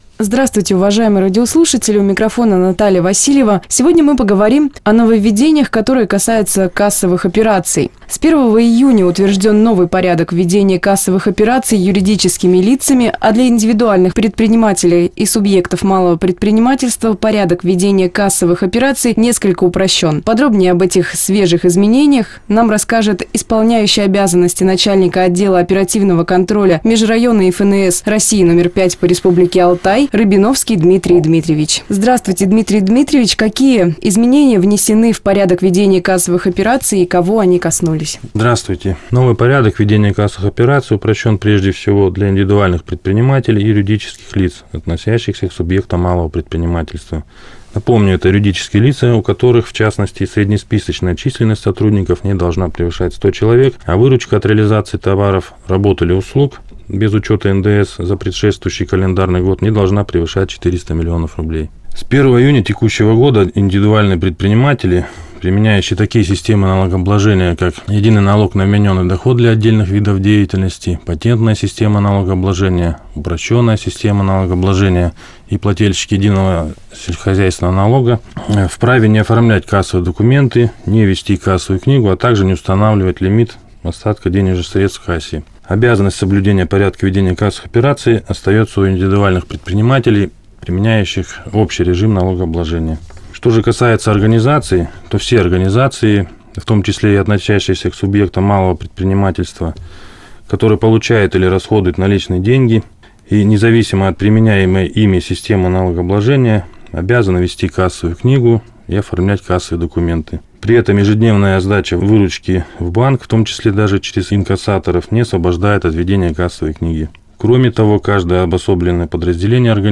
Представитель налоговой службы рассказал в эфире «Радио России» о новом Порядке ведения кассовых операций